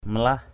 /mə-lah/ aiek: kamlah km*H